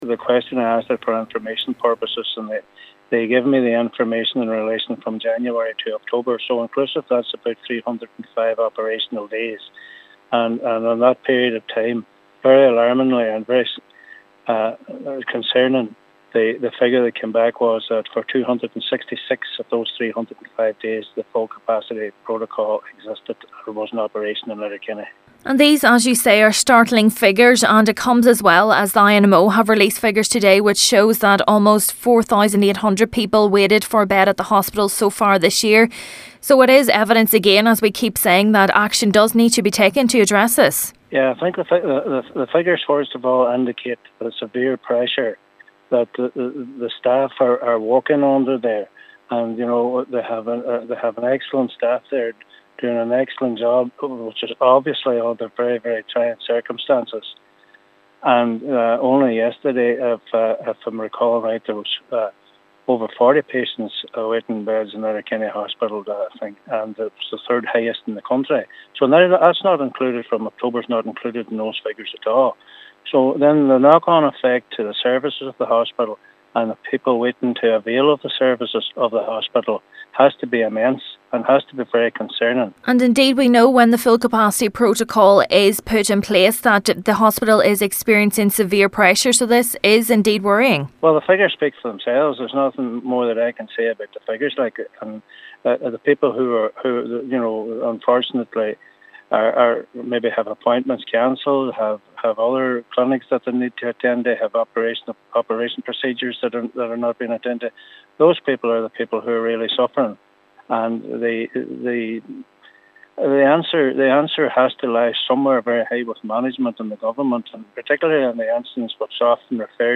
Councillor Crawford says these startling figures show the need once again for the issue to be addressed by Government: